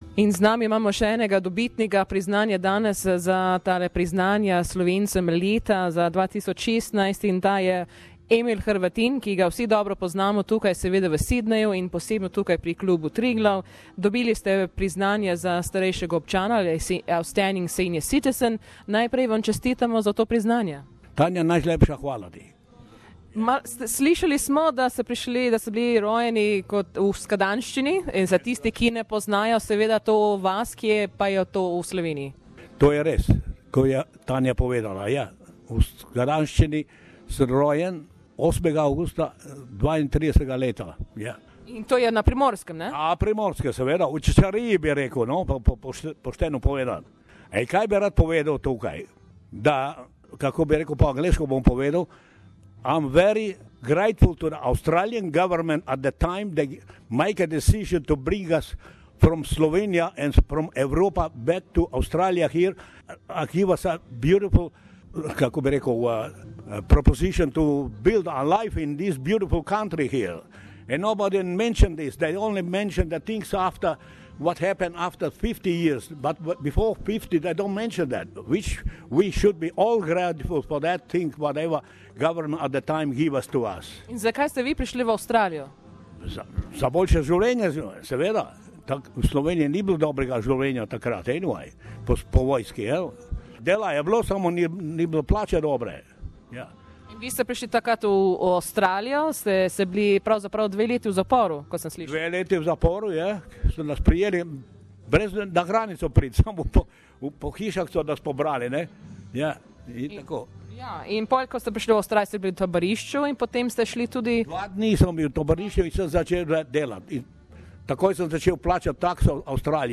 On Sunday 18th June, Klub Triglav celebrated the 26th anniversary of Slovenian National Day as well as the 15th Annual Slovenian of the Year awards for NSW. In this interview